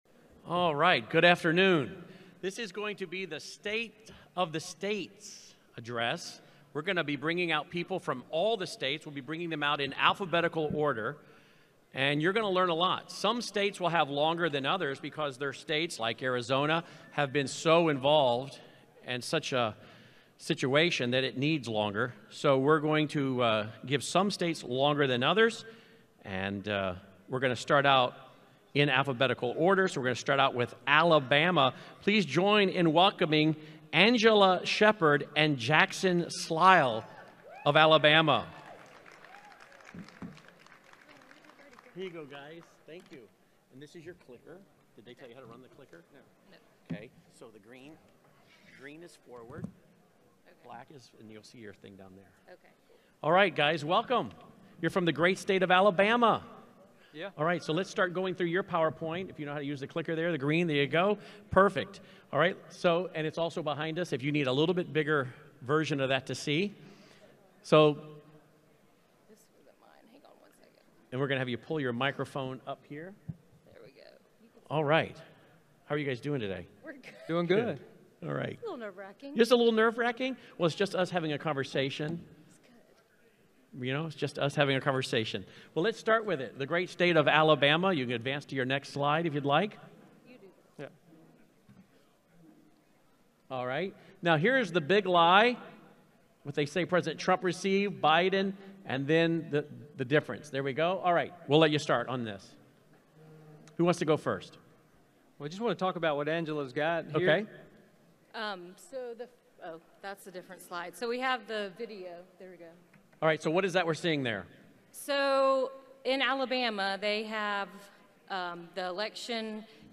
2022 AL Moment of Truth Summit State of the States Presentation audio – Cause of America